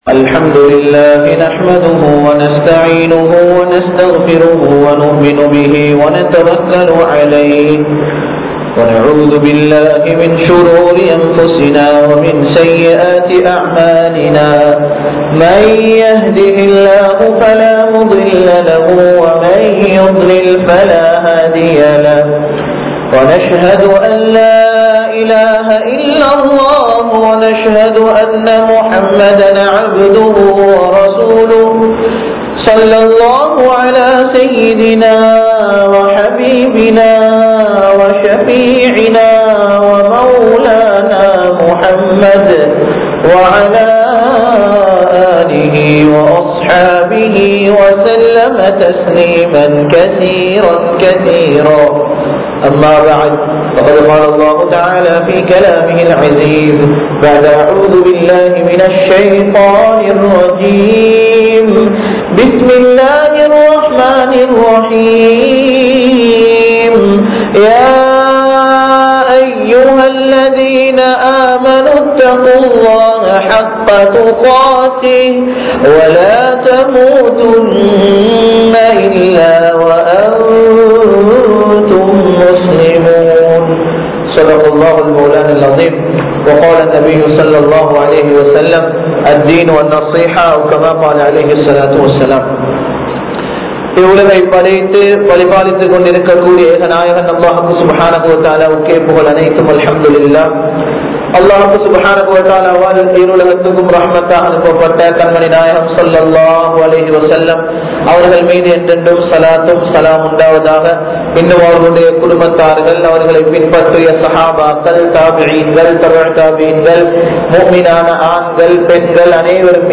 பெற்றோர்களின் பெறுமதி | Audio Bayans | All Ceylon Muslim Youth Community | Addalaichenai
Gothatuwa, Jumua Masjidh